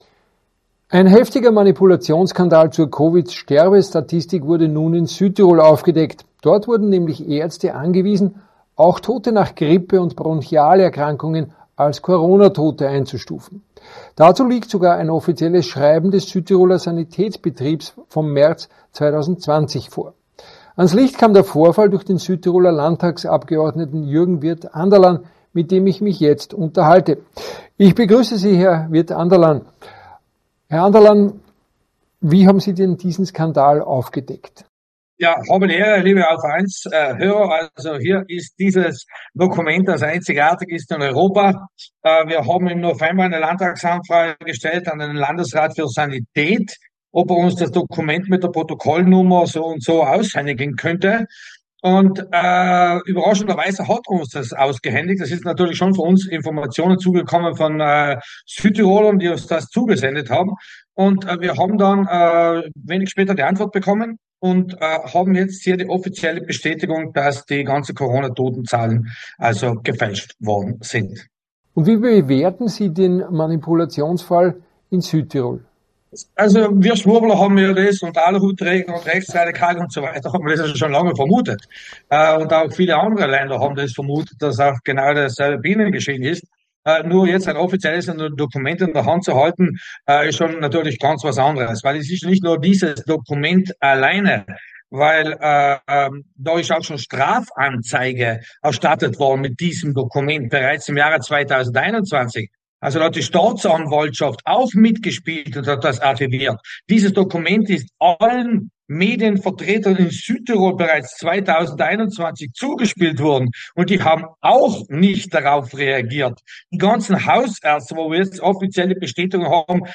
spricht er bei AUF1.